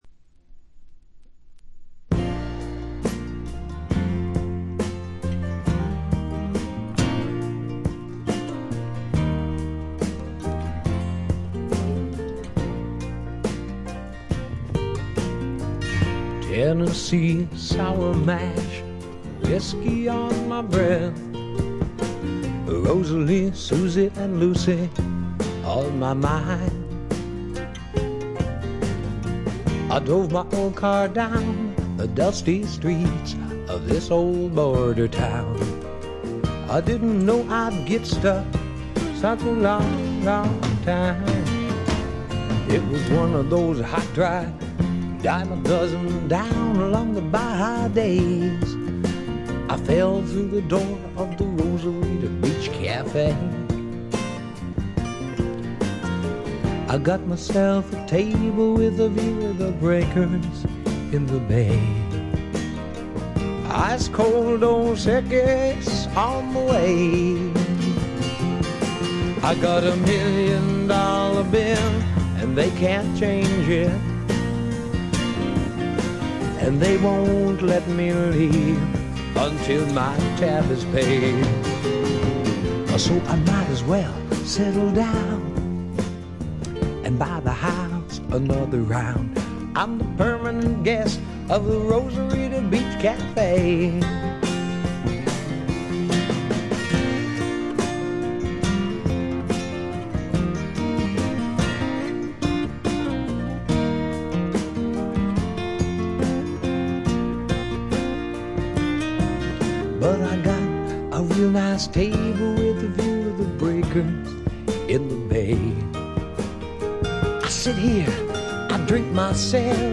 ★試聴曲は別レコードの音源です。
Vocals